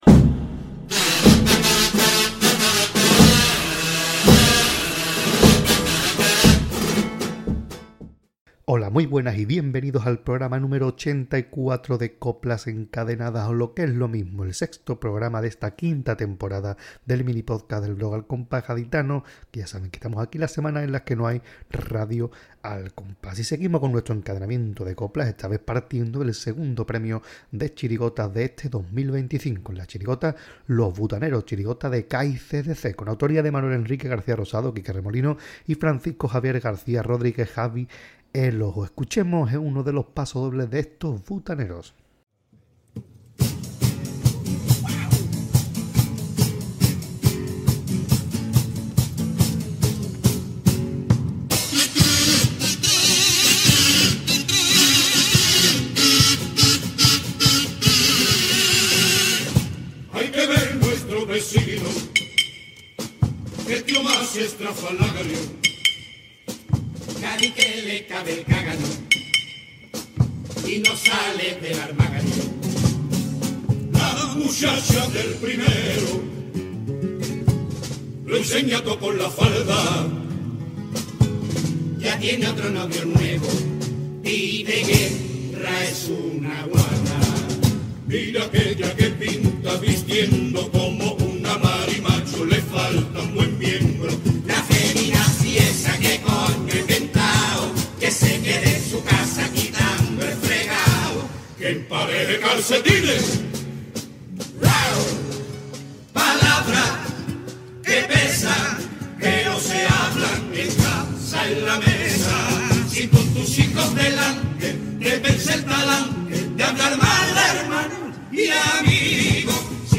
Coplas encadenadas 84 - Los butaneros, chirigota de CAI/CDC